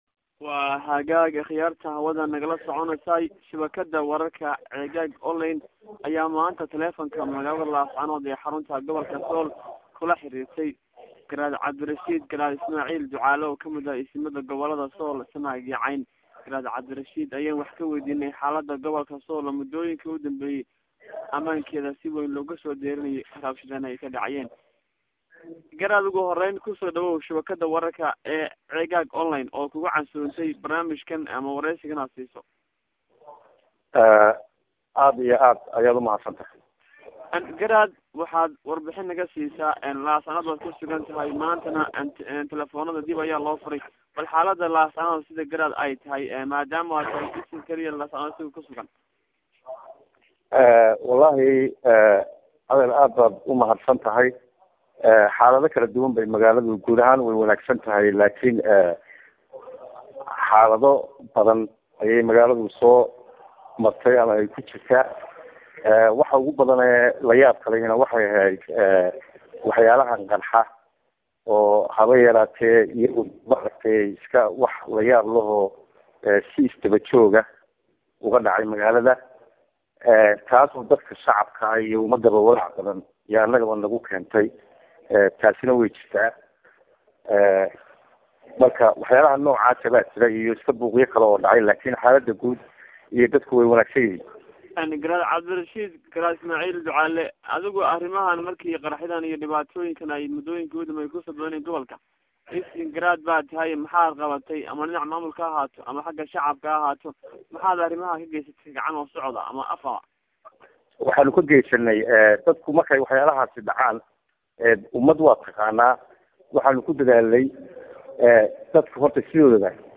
qadka  talefoonka